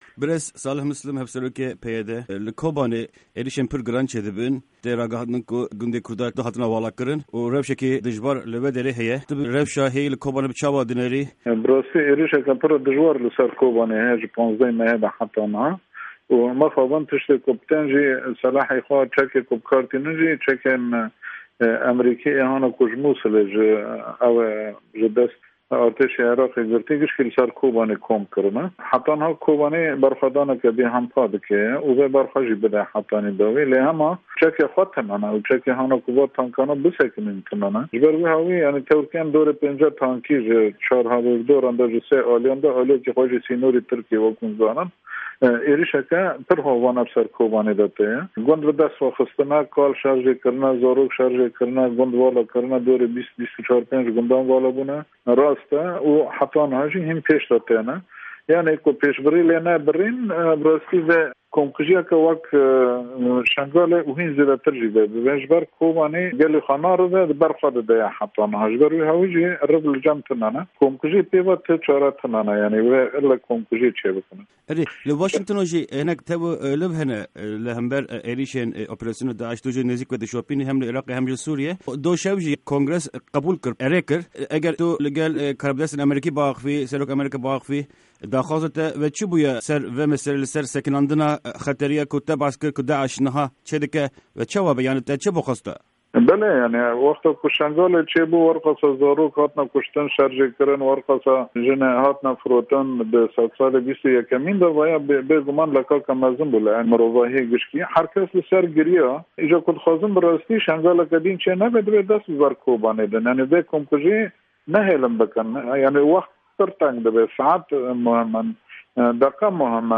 وتووێژی ساڵح موسلیم